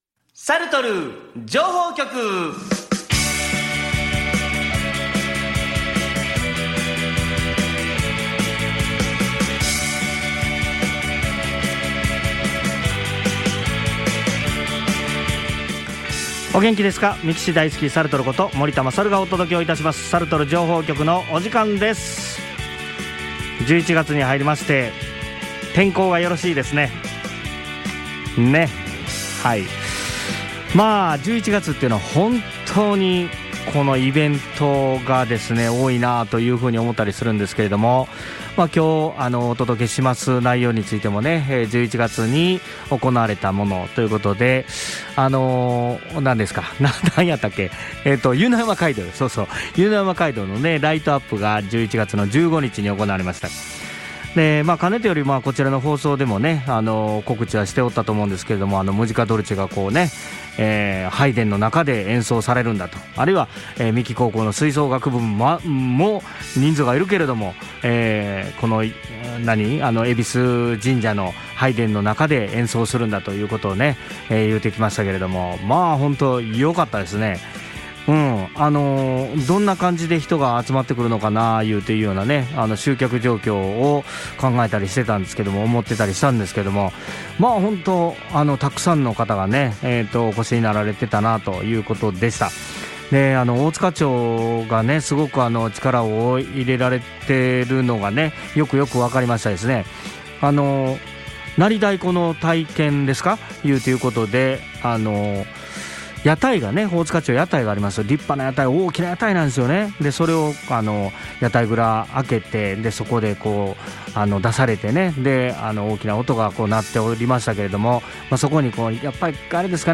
そのデザインを灯籠に使い、歴史街道「湯の山街道」を灯籠の光で包むイベントが11月15日に開催されました。 そのイベントの一環で行われた戎神社のコンサートに三木高校吹奏楽部が出演しました。